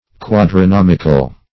quadrinomical - definition of quadrinomical - synonyms, pronunciation, spelling from Free Dictionary
Quadrinomical \Quad`ri*nom"ic*al\, a.